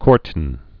(kôrtn)